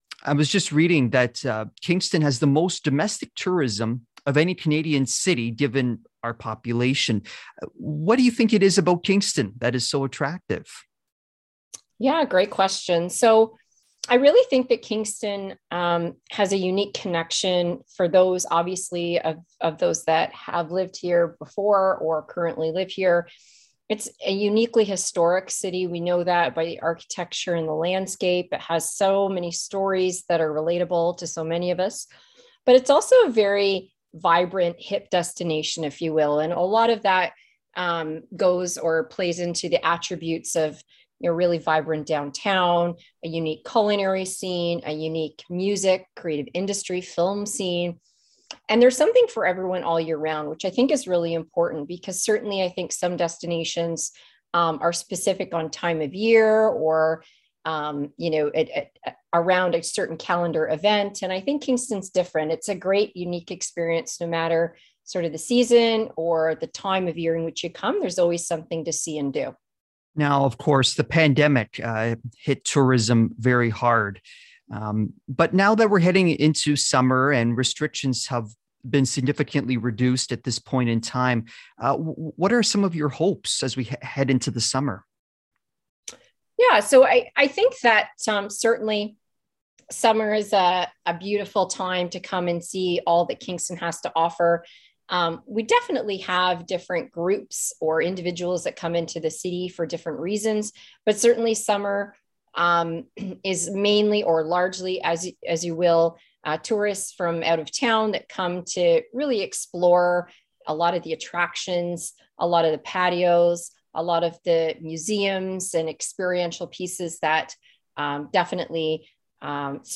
Tourism-Kingston-Interview.mp3